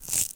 grass10.ogg